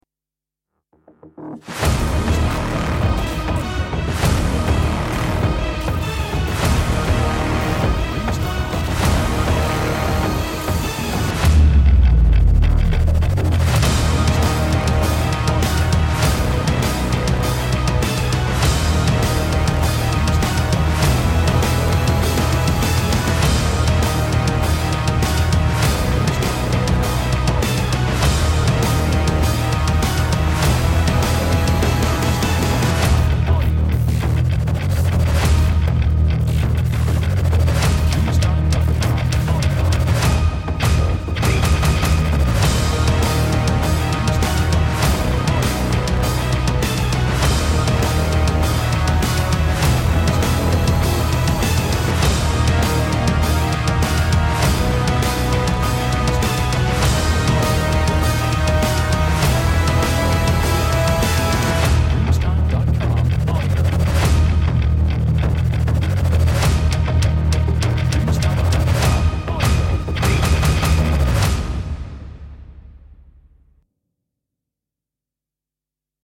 Dark Electronic Action